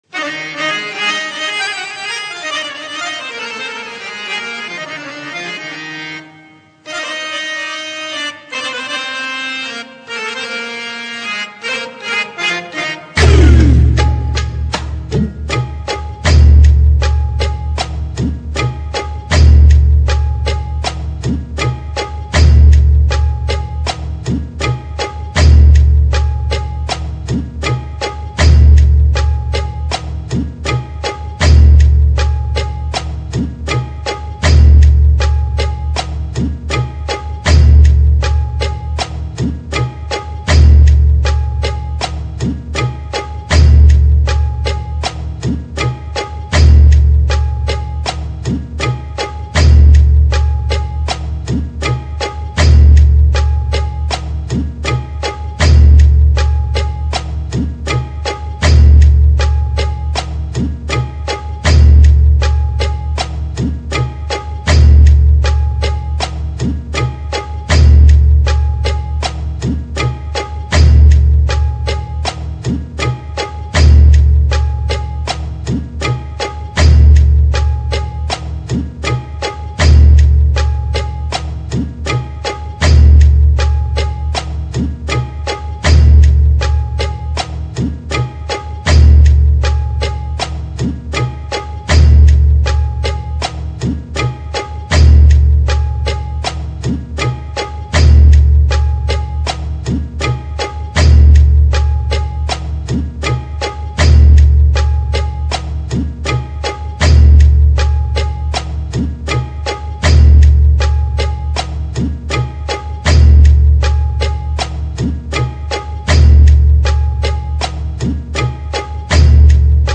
Filter Song